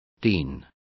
Complete with pronunciation of the translation of teens.